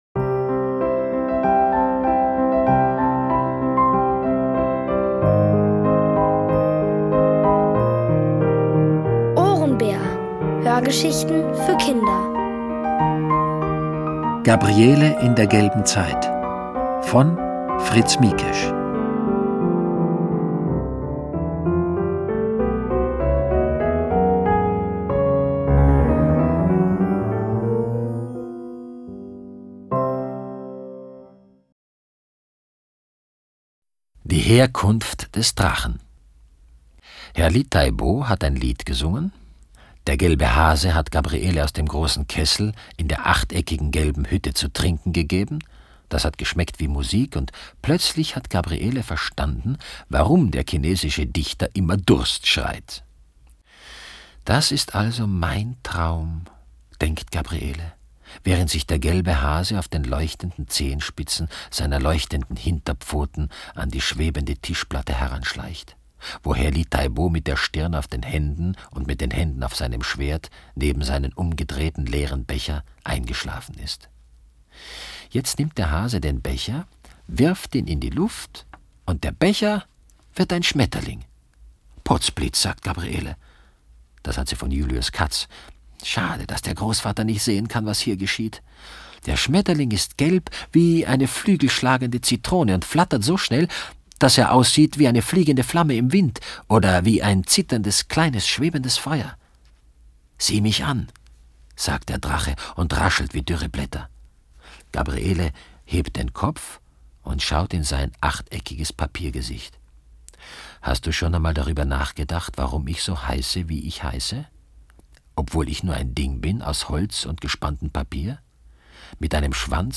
Von Autoren extra für die Reihe geschrieben und von bekannten Schauspielern gelesen.
OHRENBÄR-Hörgeschichte: Gabriele in der gelben Zeit (Folge 7 von 7)